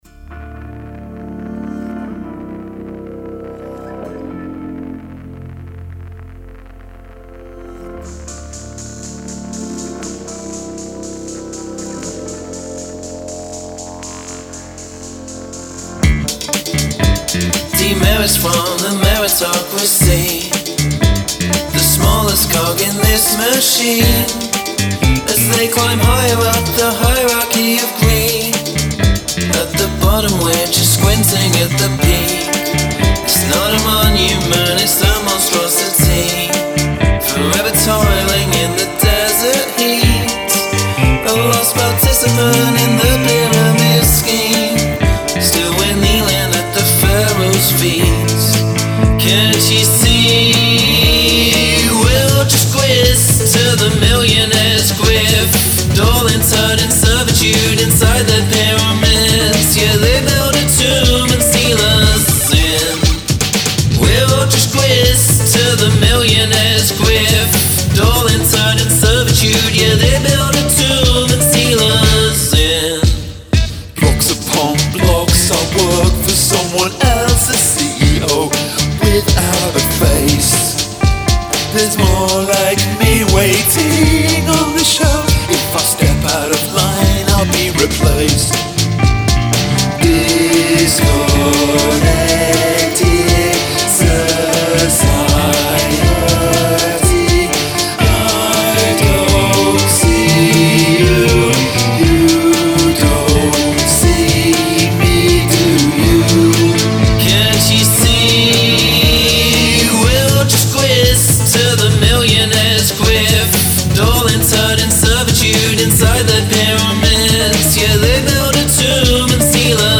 (each chorus features a shortening of the melody)